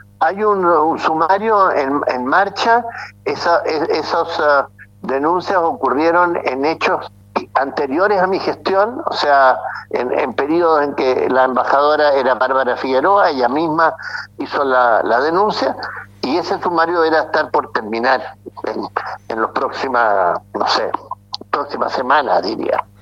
En conversación con Radio Bío Bío, el exparlamentario se refirió a los intercambios comerciales que se están realizando con el país trasandino, lo que podría traer beneficios a Chile en el área agroalimentaria, de hidrocarburos, gas y petróleo.